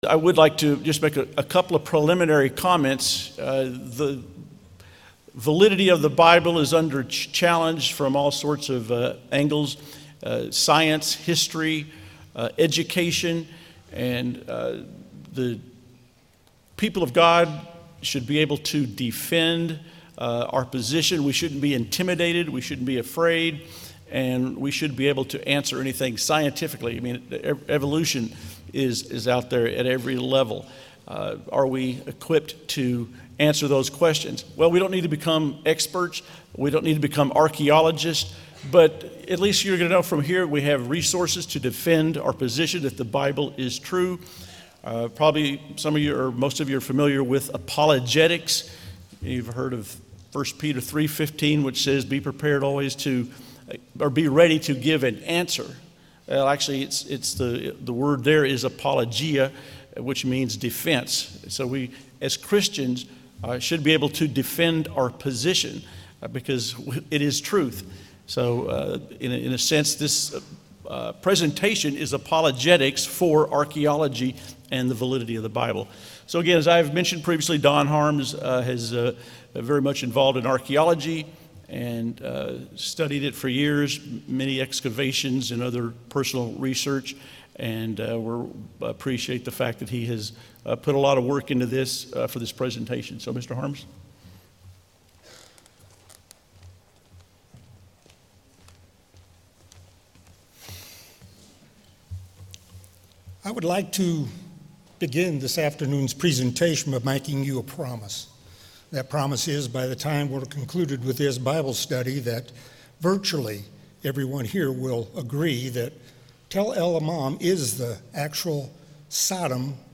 This sermon was given at the St. George, Utah 2022 Feast site.